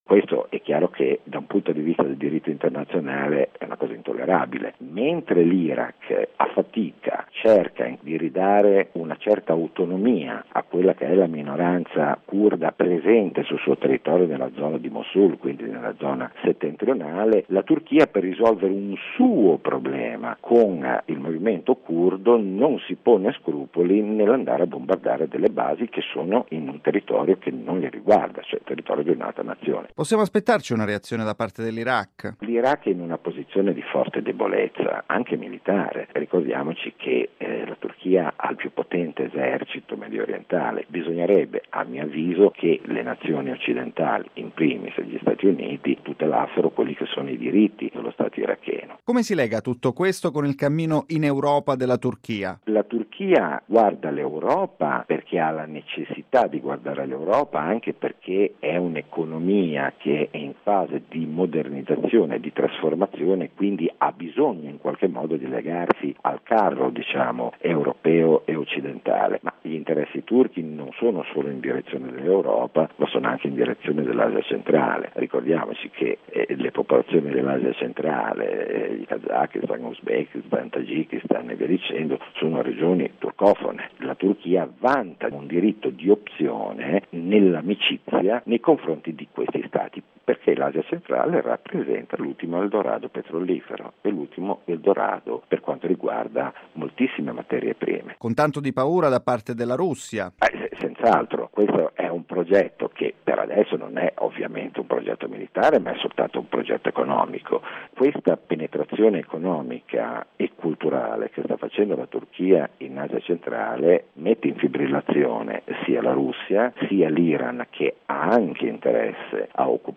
giornalista ed esperto dell’area: